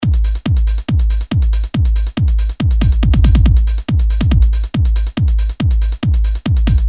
调情的人鼓声
标签： 140 bpm Trance Loops Drum Loops 1.15 MB wav Key : Unknown
声道立体声